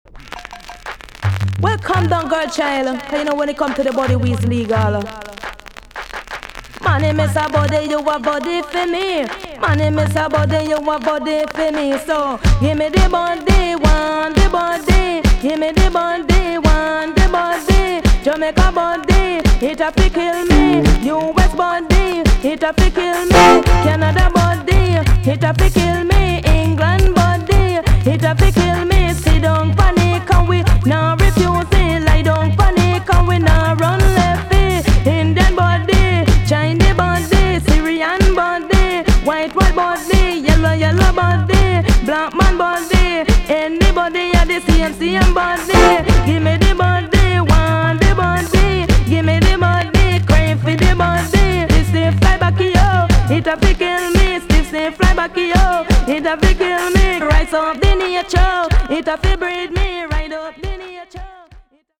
TOP >80'S 90'S DANCEHALL
VG ok 全体的にチリノイズが入ります。
NICE FEMALE DJ TUNE!!